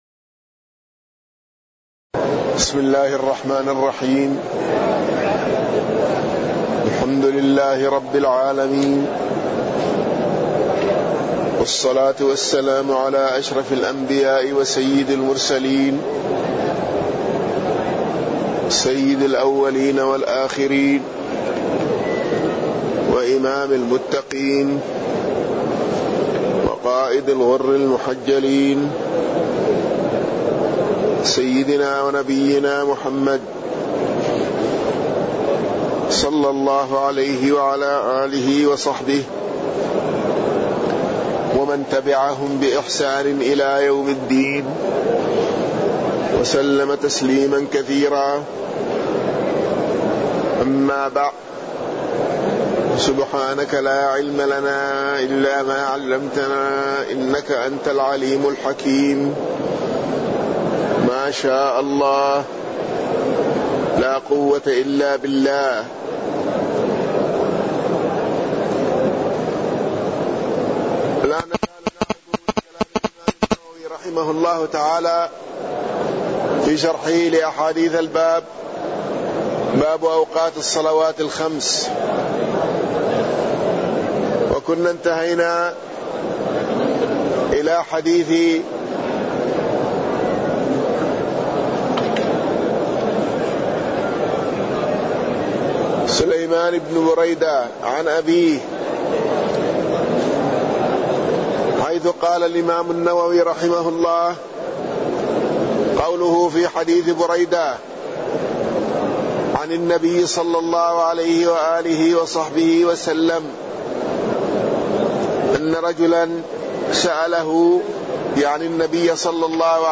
تاريخ النشر ٥ ذو القعدة ١٤٢٩ هـ المكان: المسجد النبوي الشيخ